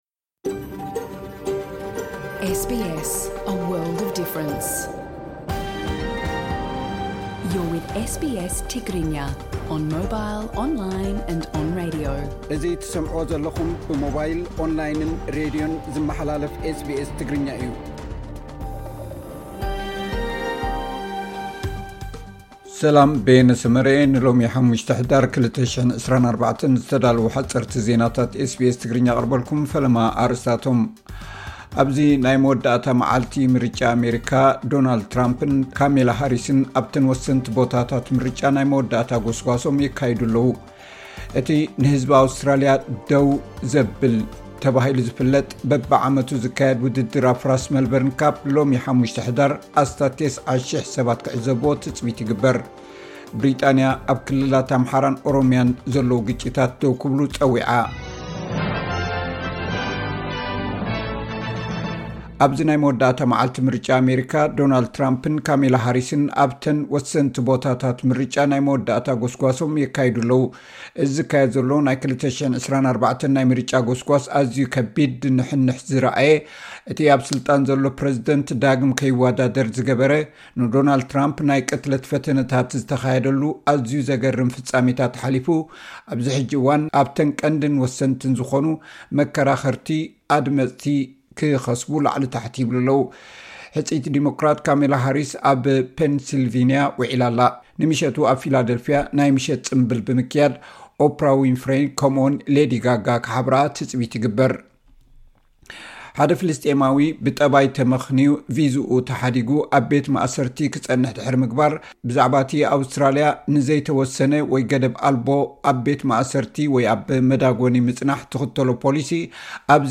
ሓጸርቲ ዜናታት ኤስ ቢ ኤስ ትግርኛ (05 ሕዳር 2024)